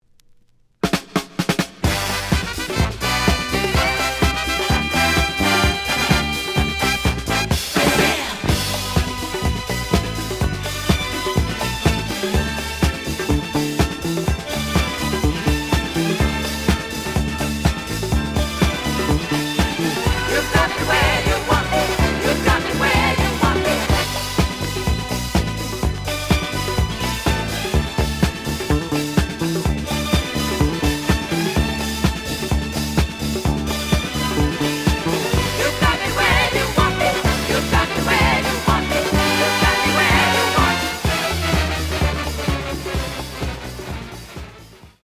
試聴は実際のレコードから録音しています。
●Genre: Disco
●Record Grading: VG~VG+ (A面のラベルにステッカー。傷はあるが、プレイはおおむね良好。)